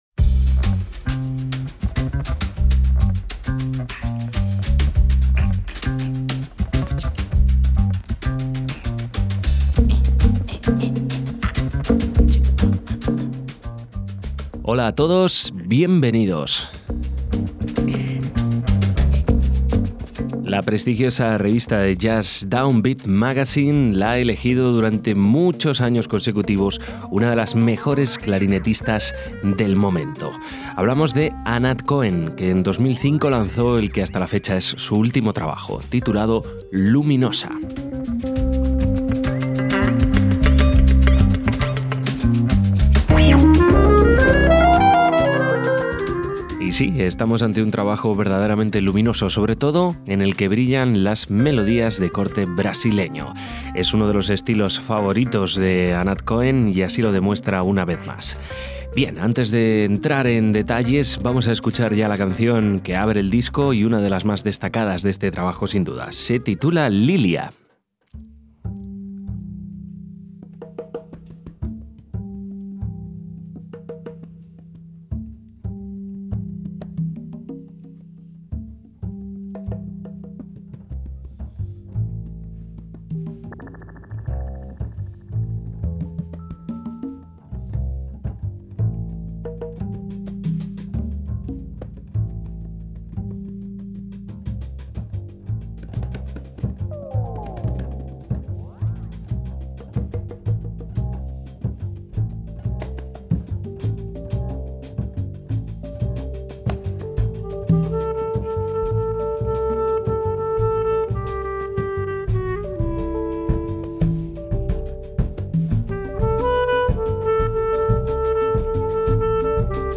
piano
contrabajo
Clarinete jazz israelí